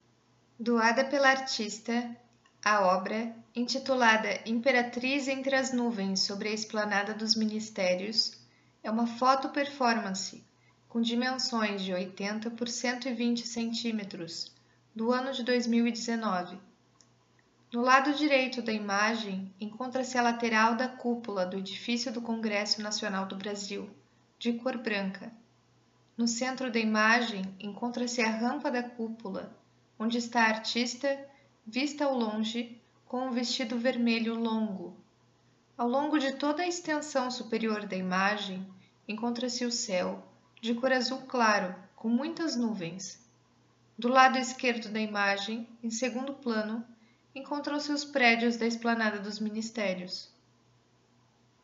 audiodescrição Elle de Bernardini